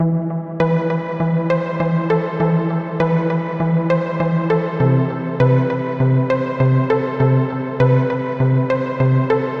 Tag: 100 bpm Cinematic Loops Synth Loops 1.62 MB wav Key : E